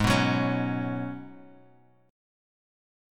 G#m11 Chord
Listen to G#m11 strummed